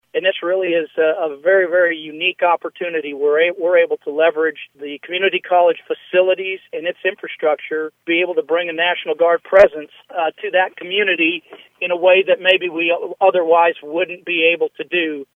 *On-air story*
tafanelli-commencement-two.mp3